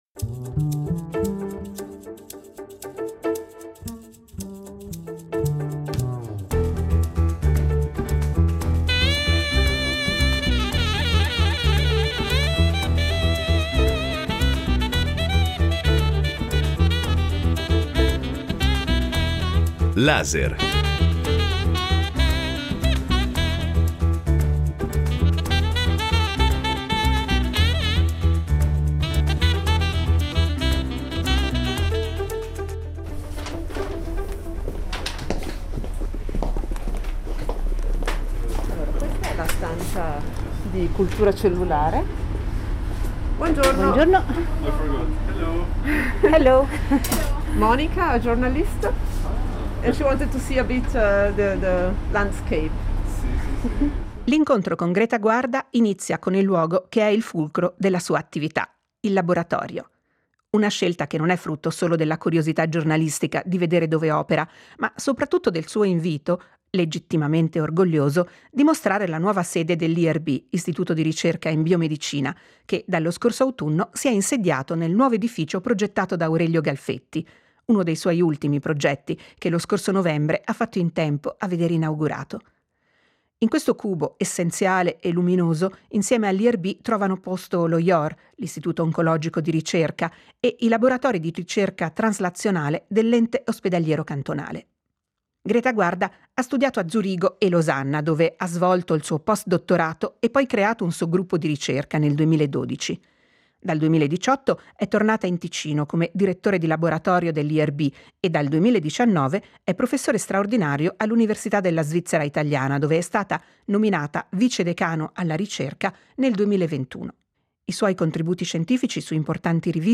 Incontro